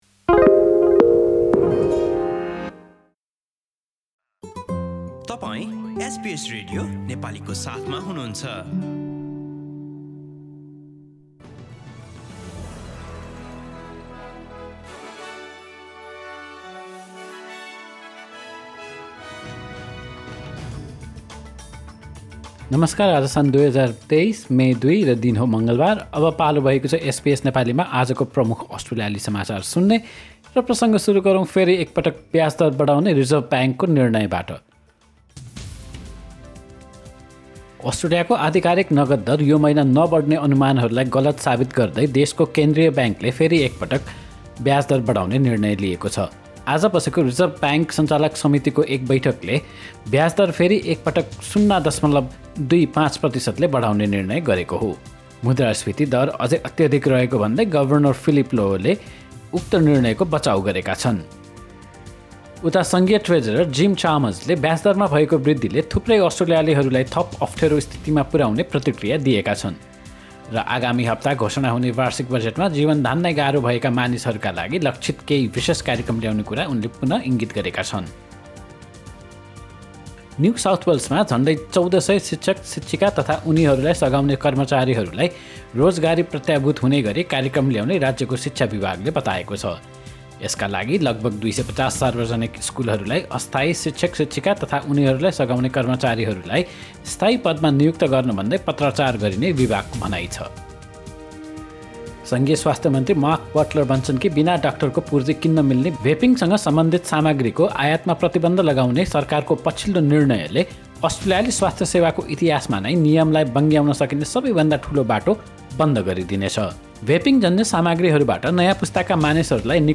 एसबीएस नेपाली प्रमुख अस्ट्रेलियाली समाचार : मङ्गलवार, २ मे २०२३